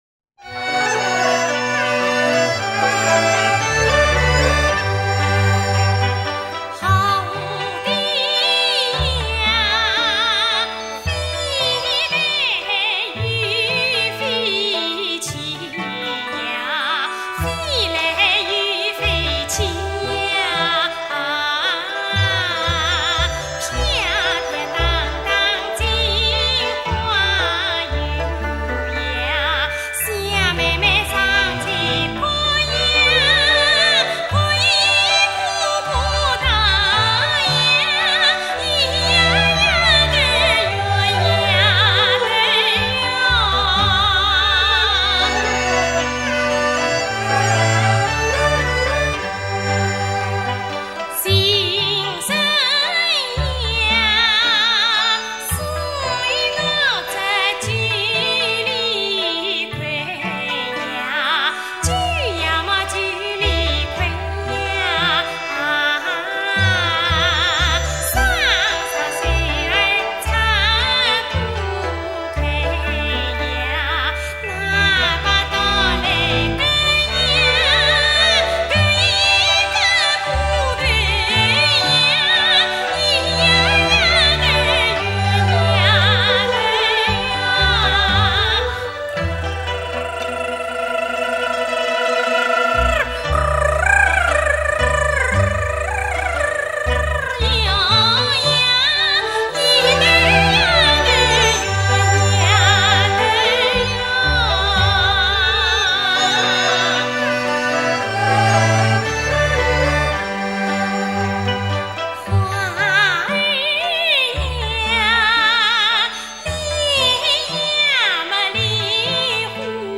回复: [15/9/2009]原生态系列 江苏原生态民歌 比较地道！ 小九连环 苏州、无锡地区民歌 蝴蝶呀飞来又飞去，飞来又飞去呀。